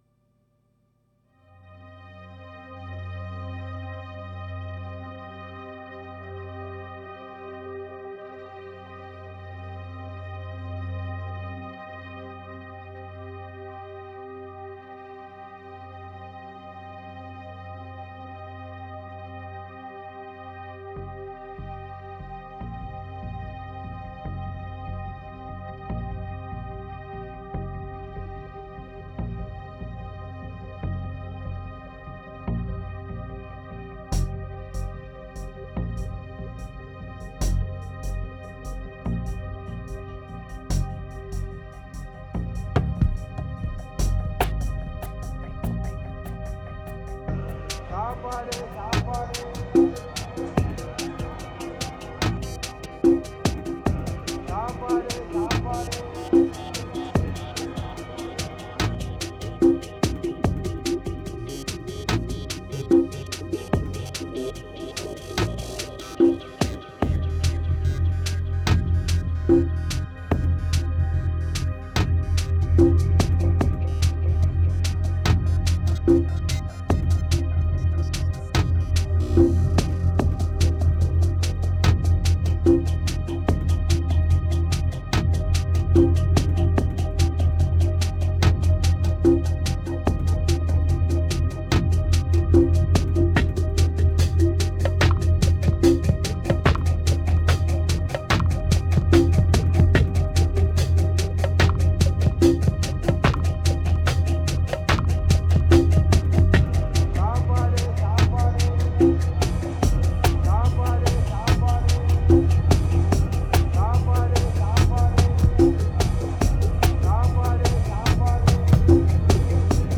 2670📈 - 67%🤔 - 73BPM🔊 - 2011-01-07📅 - 232🌟